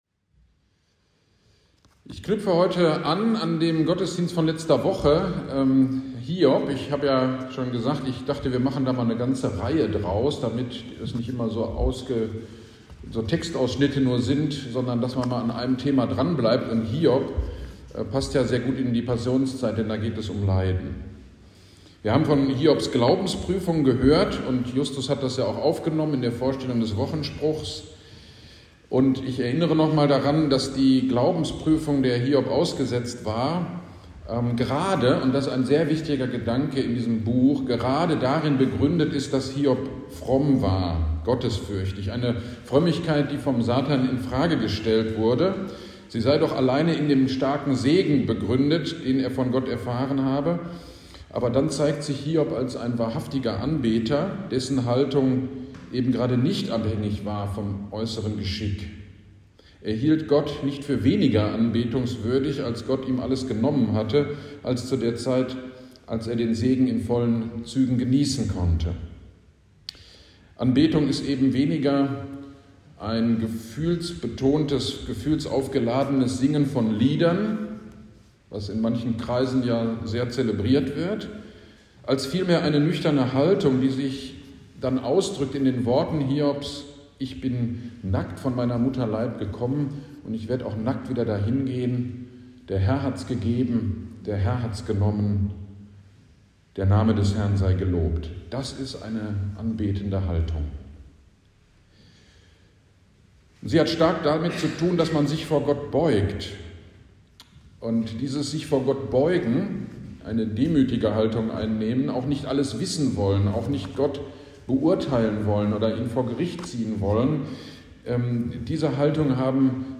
GD am 05.03.23 Predigt zu Hiob 2